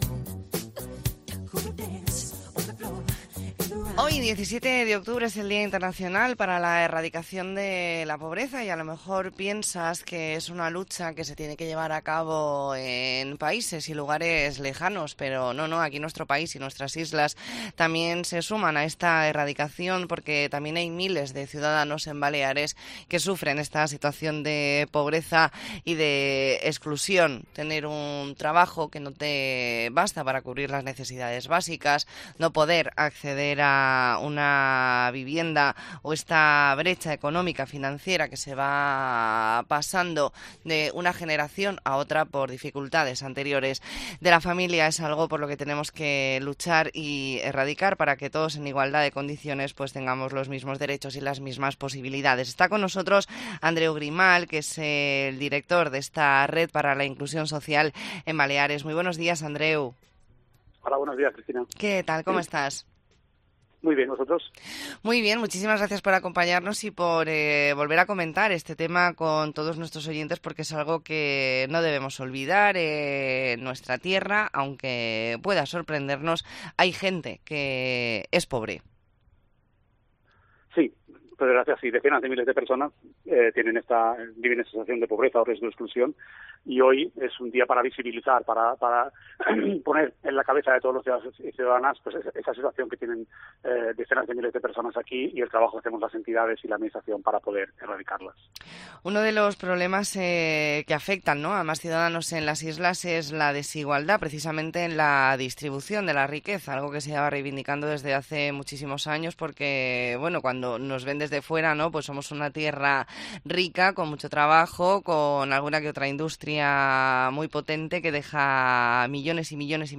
Entrevista en La Mañana en COPE Más Mallorca, martes 17 de octubre de 2023.